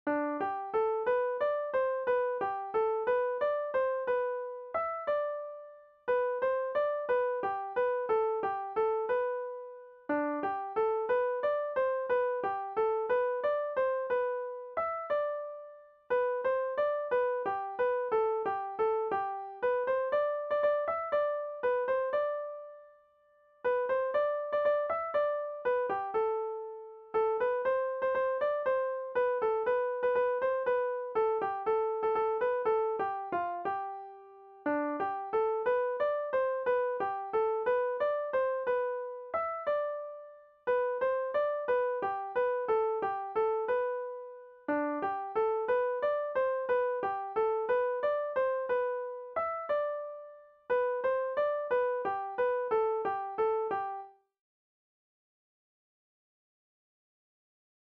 Valse-polka
danse : polka-valse